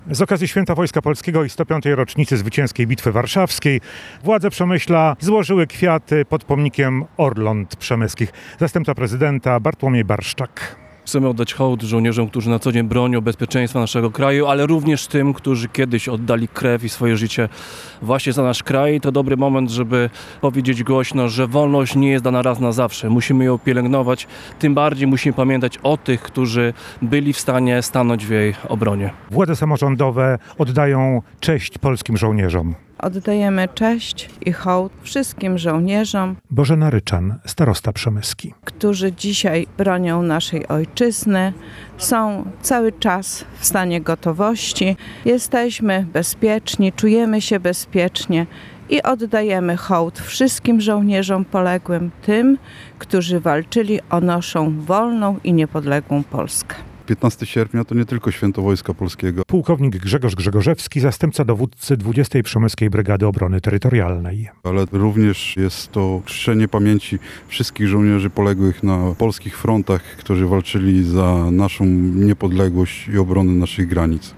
Swieto-Wojska-Polskiego-Przemysl.mp3